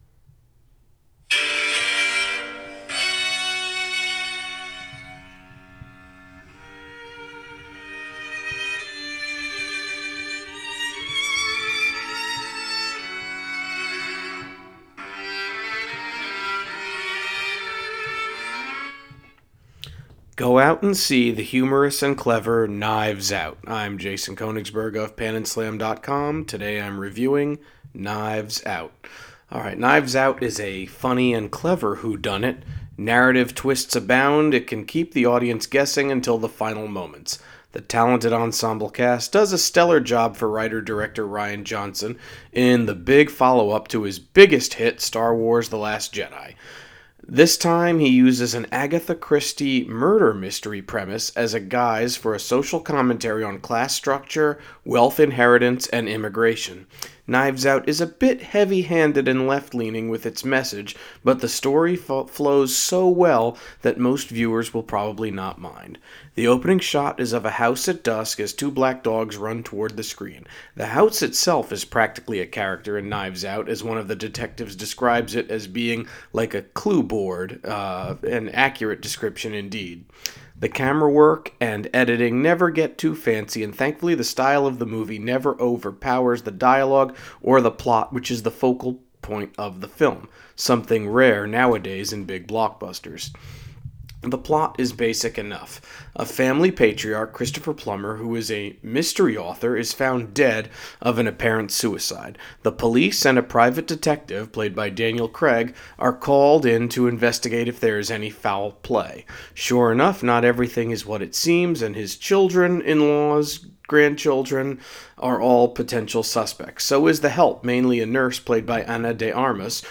Movie Review: Knives Out